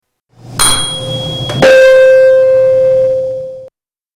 Tuning fork 9
Category: Sound FX   Right: Personal